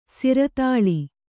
♪ siratāḷi